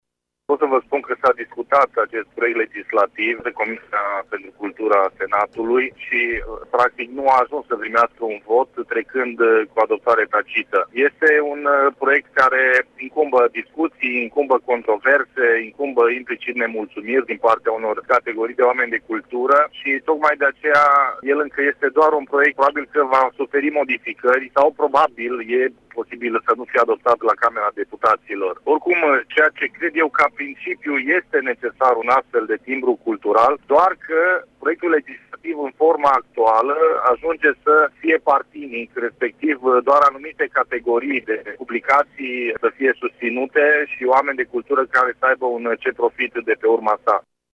Vicepreşedintele Comisiei de Cultură din Senat, Marius Paşcan, a explicat că iniţiativa este discriminatorie pentru că nu se aplică tuturor creatorilor: